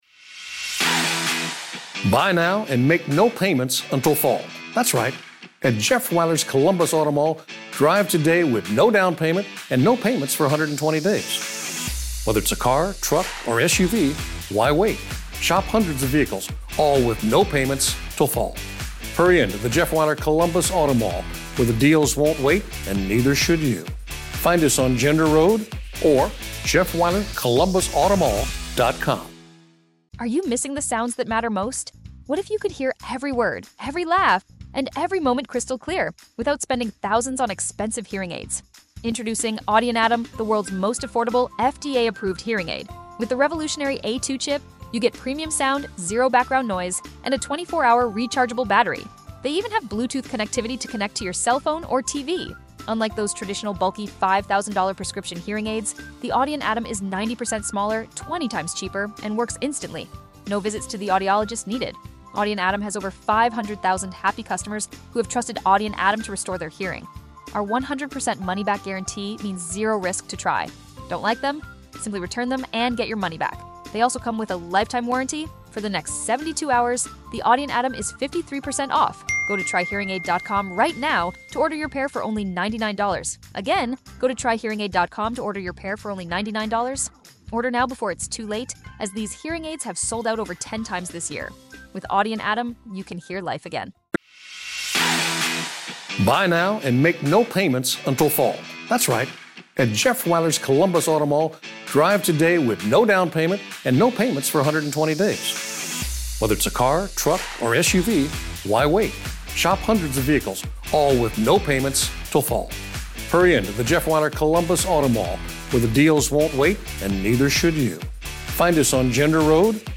True Crime, Society & Culture, Documentary, History